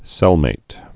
(sĕlmāt)